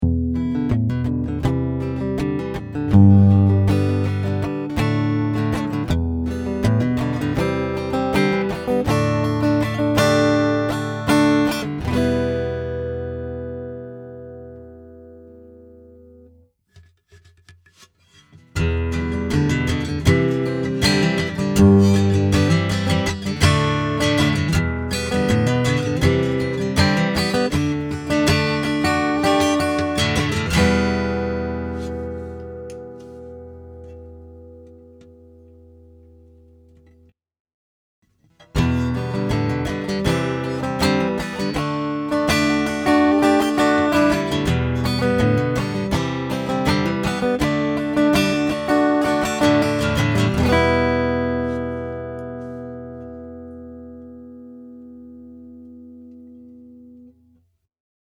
The first three were recorded completely dry. No EQ, no compression. I play the same riff three times in each clip, varying the amount of condenser mic in each. The first part isolates the magnetic pickup with no condenser, the second part has the condenser opened up wide. The third part has the condenser mic set to about 50%.
Percussive Strum
percussivestrum.mp3